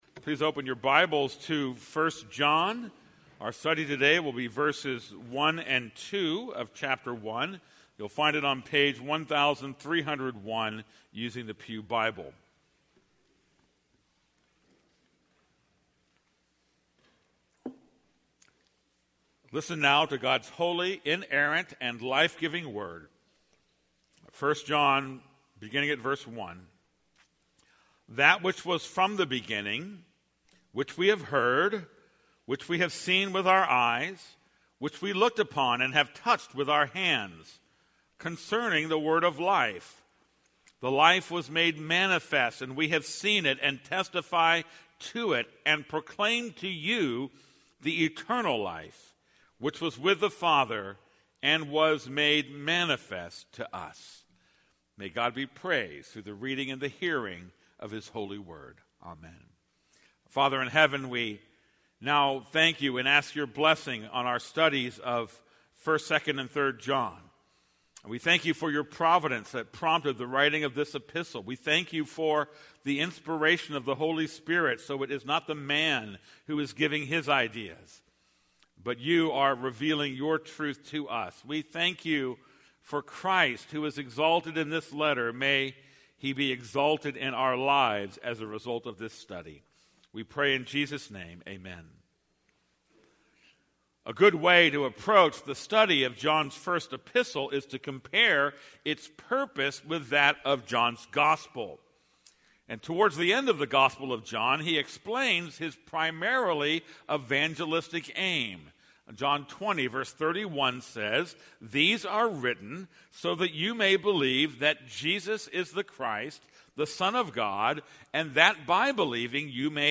This is a sermon on 1 John 1:1-2.